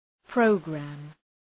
Προφορά
{‘prəʋgræm}